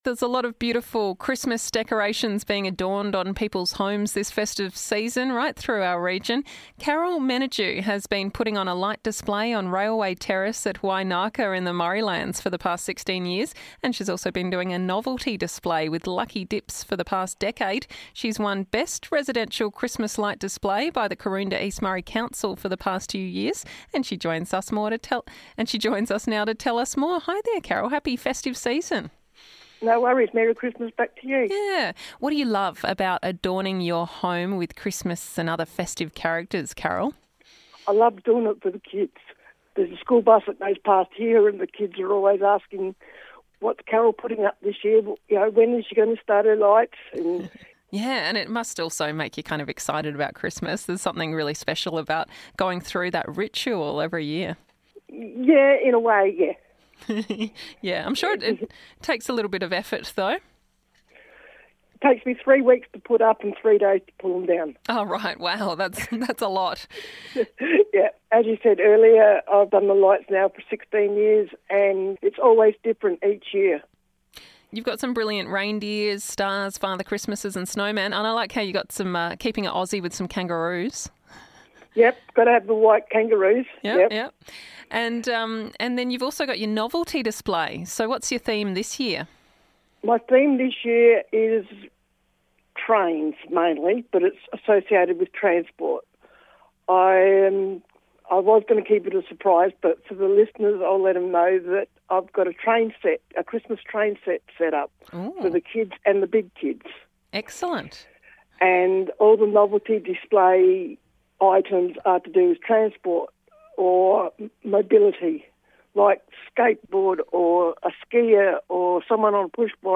a chat about it.